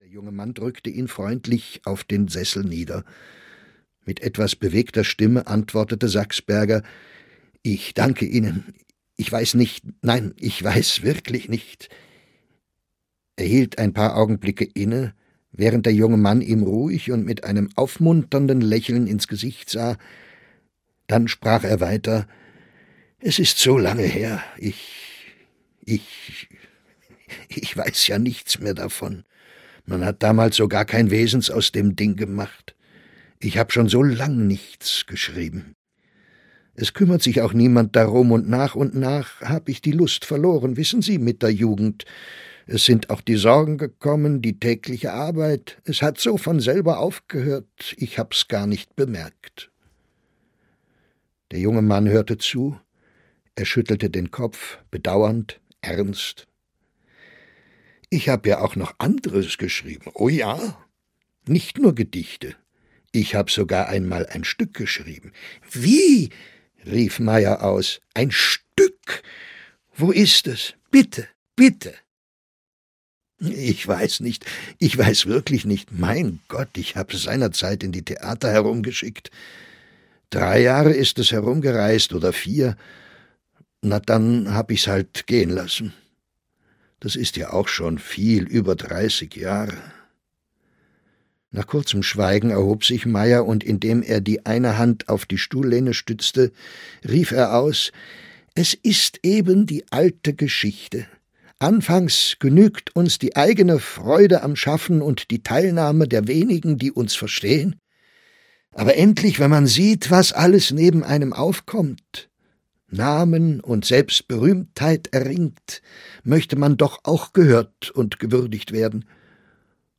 Später Ruhm - Arthur Schnitzler - E-Book + Hörbuch